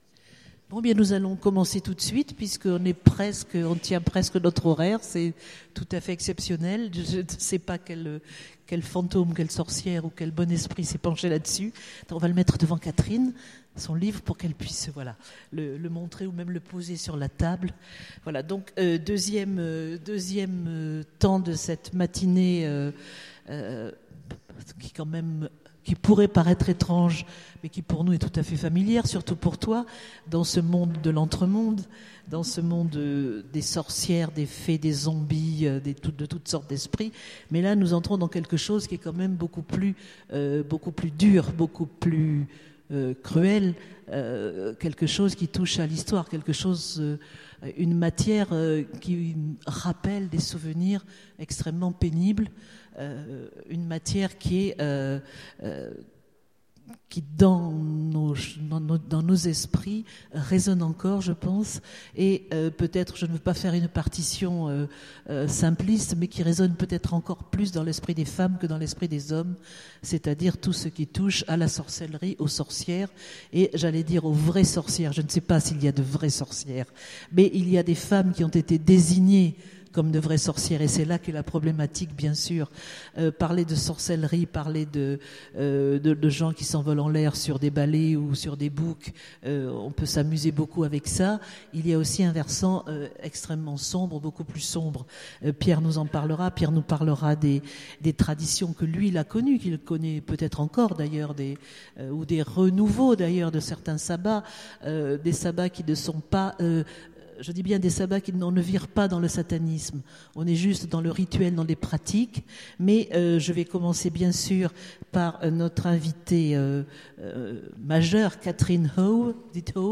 Étonnants Voyageurs 2015 : Conférence Sorcières de Salem